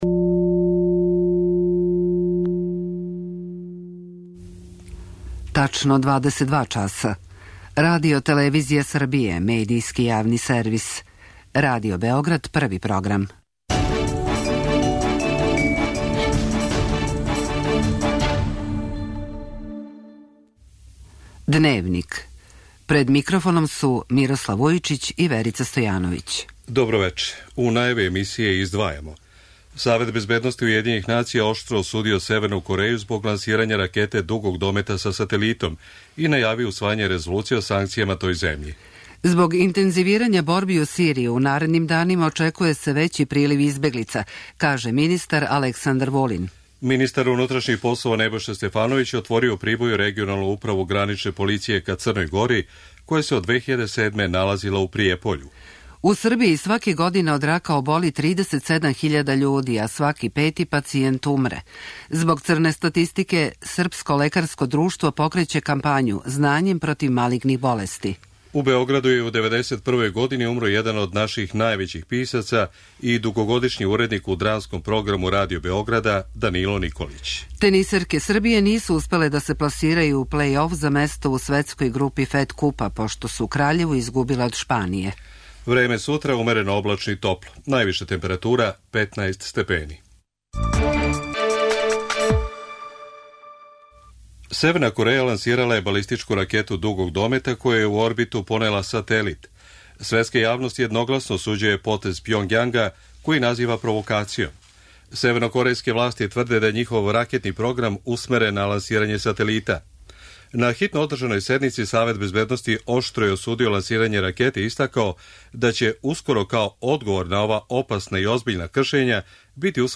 Dnevnik